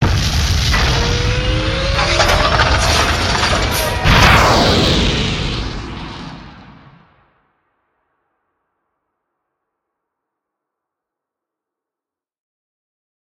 otherlaunch.ogg